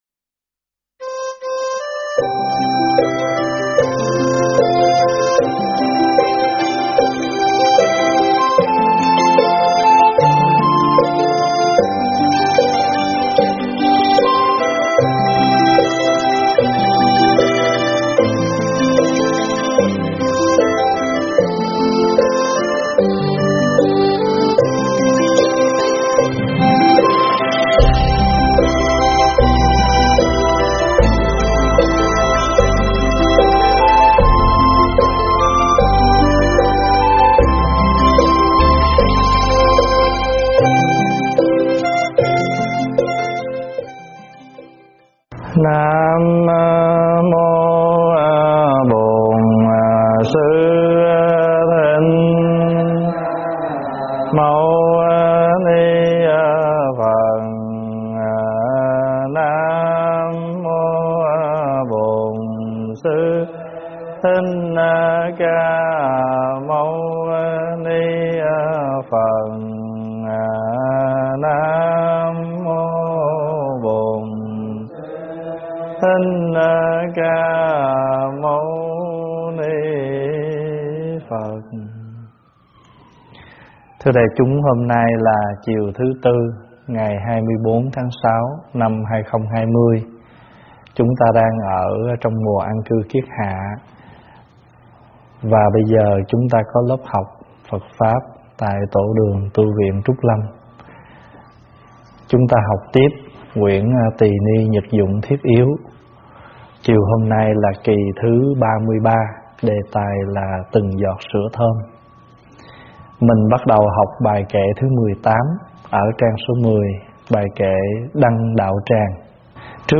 Nghe mp3 thuyết pháp Từng Giọt Sữa Thơm 33 - Kệ đăng đạo tràng do ĐĐ. Thích Pháp Hòa giảng tại Tv Trúc Lâm, Ngày 24 tháng 6 năm 2020